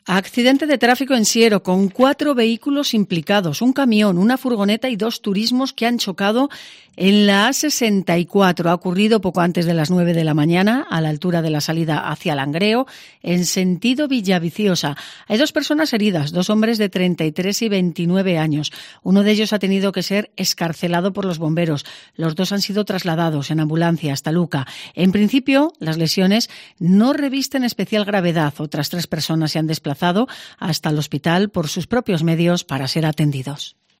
Así hemos contado en COPE el accidente de tráfico en Siero